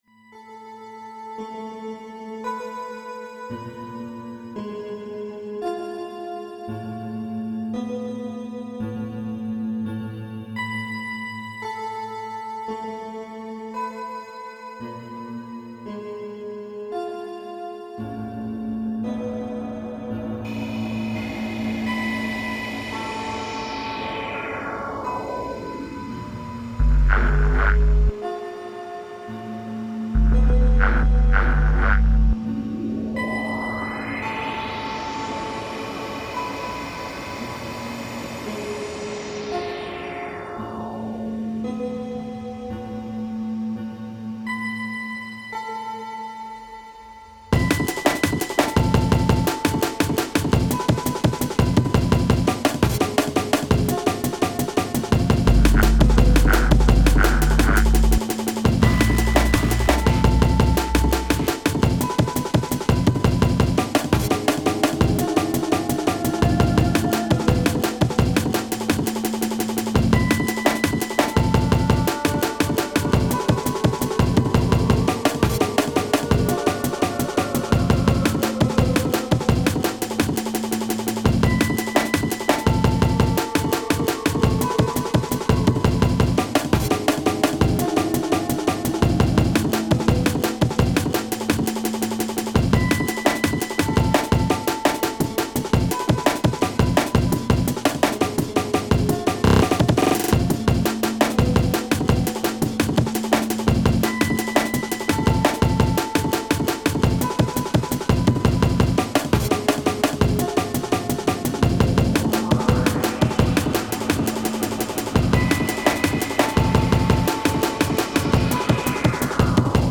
breakbeat amen